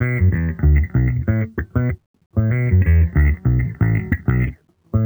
Index of /musicradar/sampled-funk-soul-samples/95bpm/Bass
SSF_JBassProc2_95B.wav